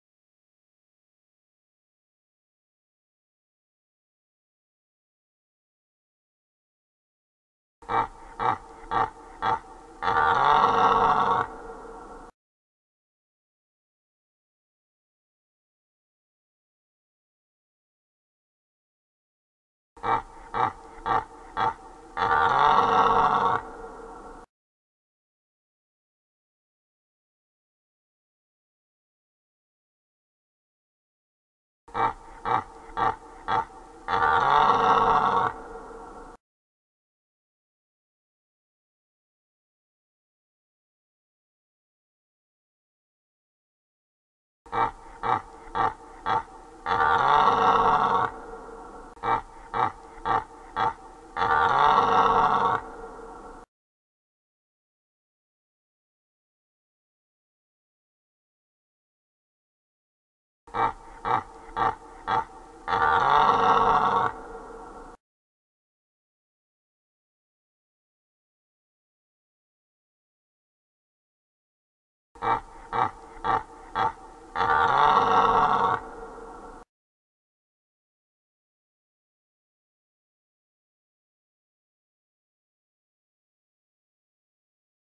Sonido del Ciervo Videos Para Niños.mp3
Sonido del Ciervo  Videos Para Niños.mp3